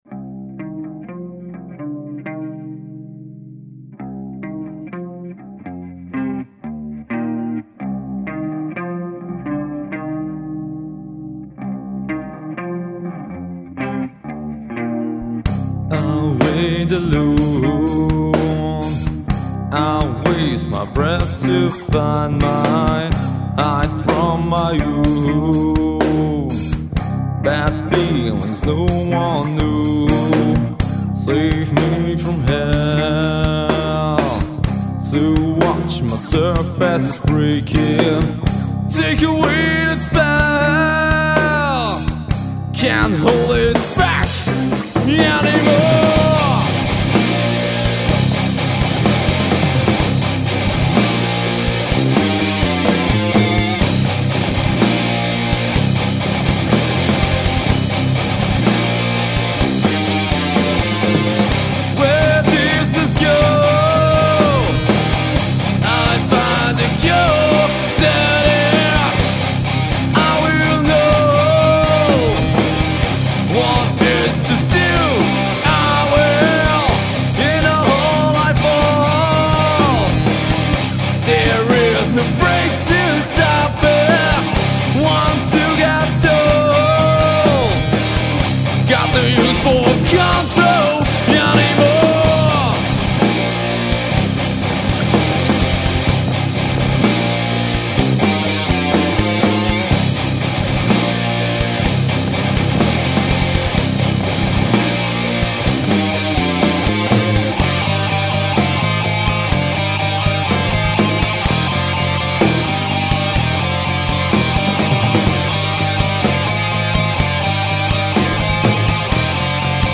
Mischung aus Metal und Rock
Guitar & Vocals
Bass
Drums